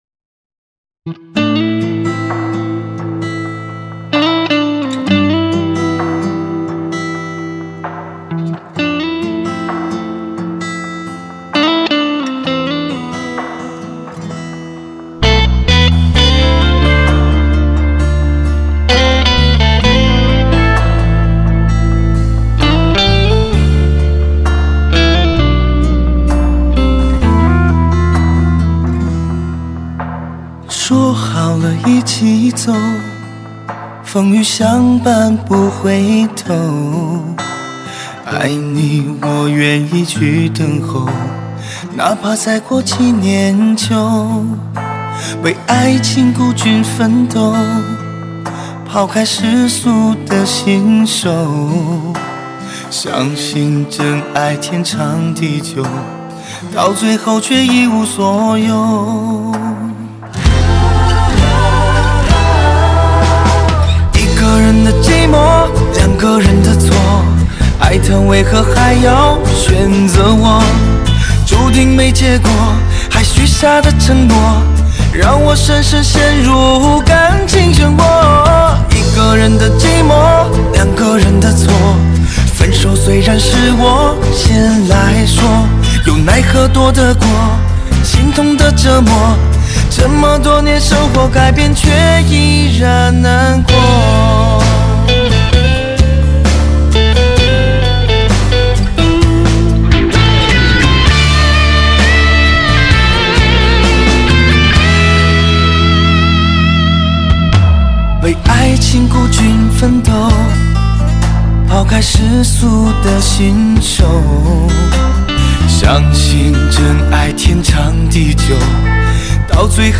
类别: 流行